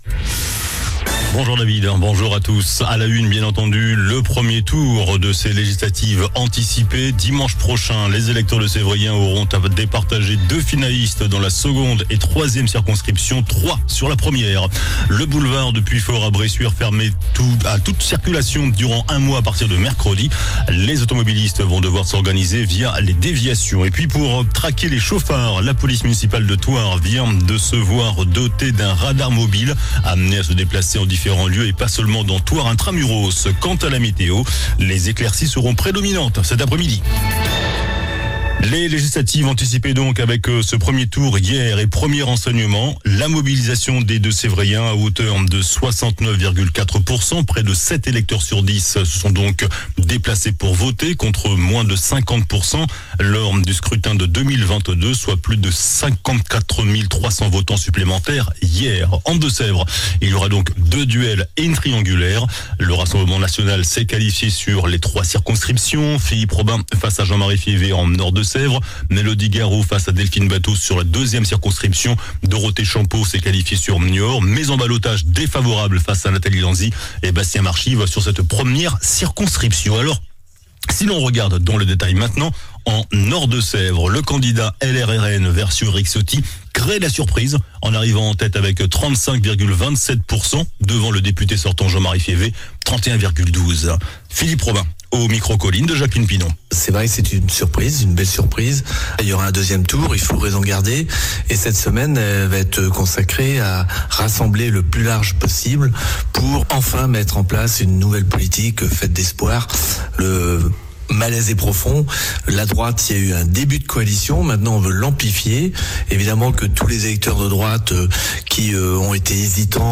JOURNAL DU LUNDI 01 JUILLET ( MIDI )